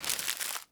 trash.aif